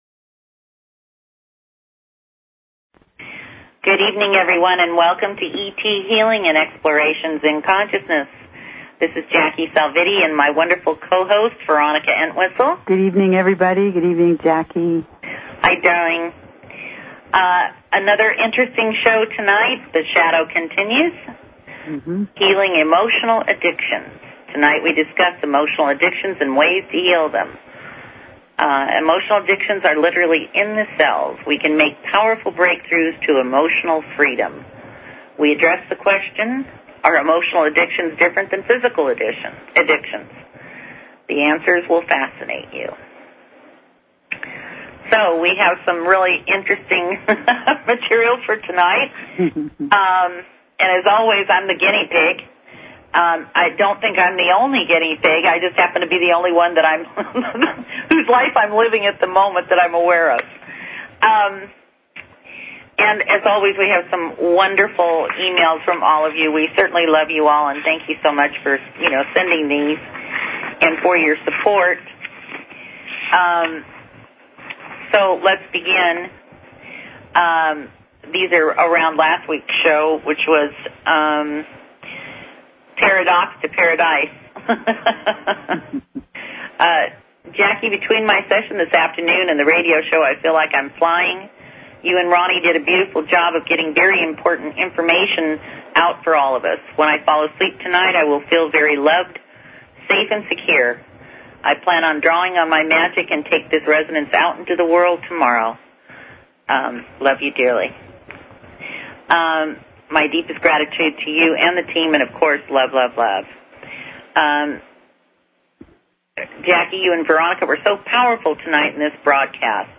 Talk Show Episode, Audio Podcast, ET_Healing and Courtesy of BBS Radio on , show guests , about , categorized as